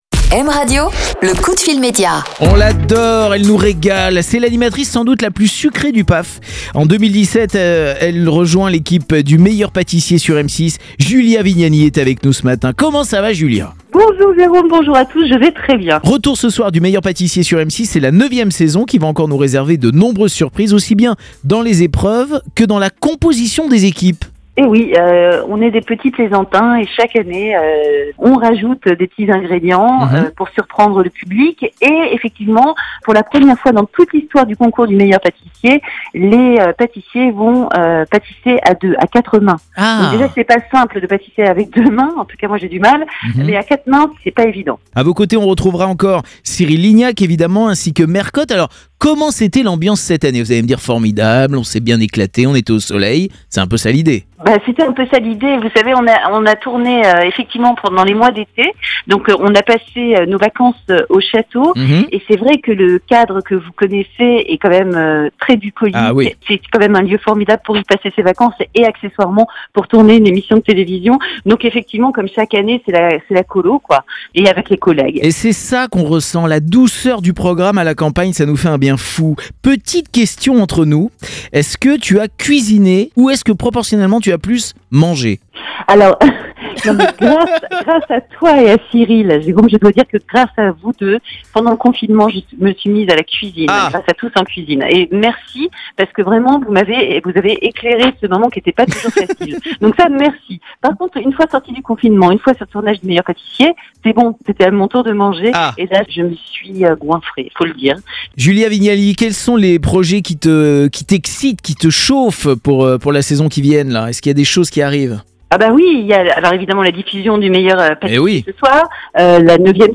Ce matin Jerôme Anthony recevait Julia Vignali, aux commandes de l'émission "Le Meilleur Patissier" de retour ce soir sur M6